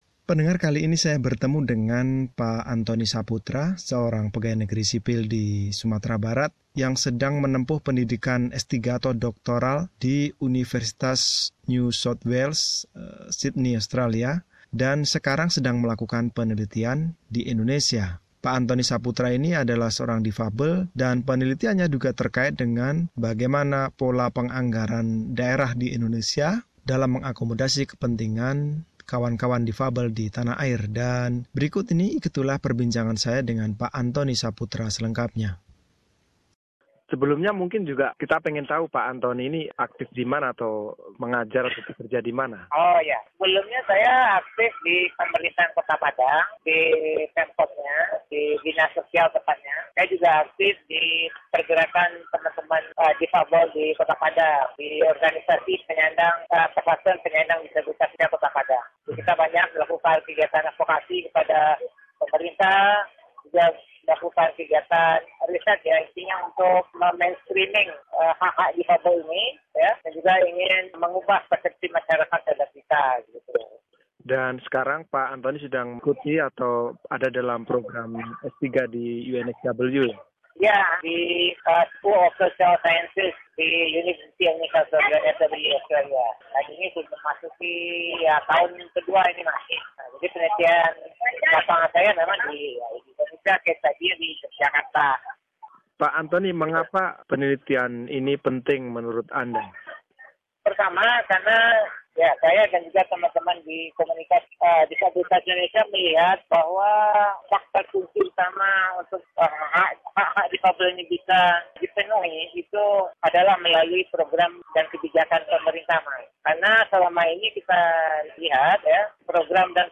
Dalam wawancara ini